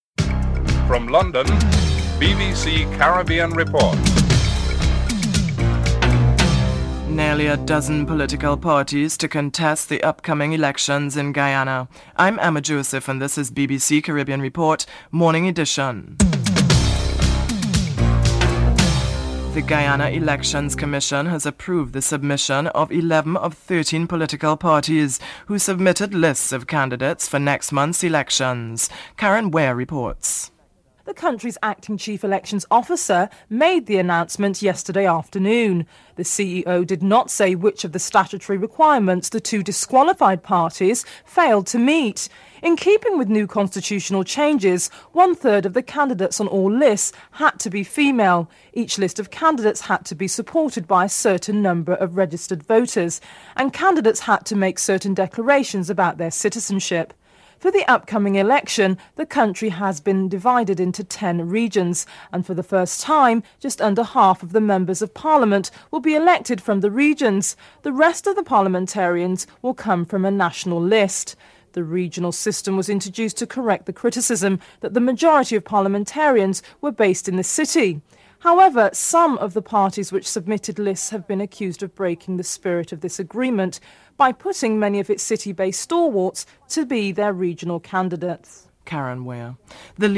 1. Headlines (00:00-00:19)
The Mighty Shadow is interviewed (08:08-09:07)